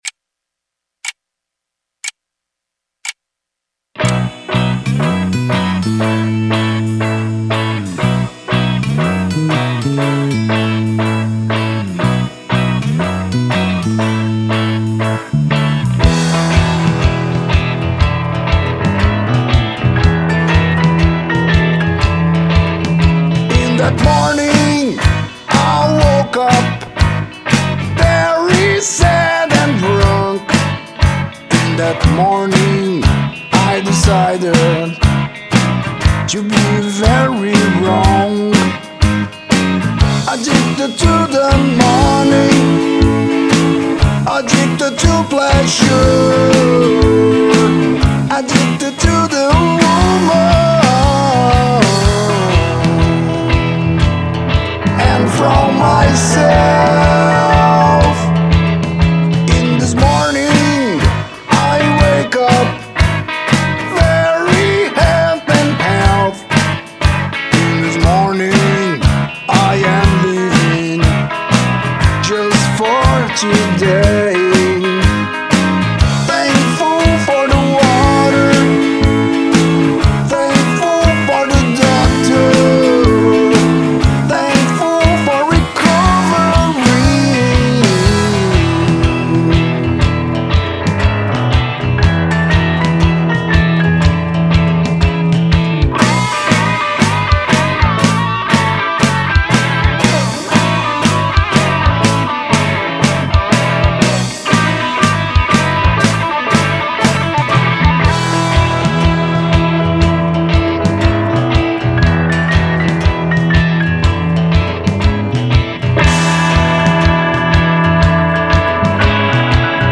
EstiloRock
A influência dos Beatles é clara, e eu não a escondo aqui.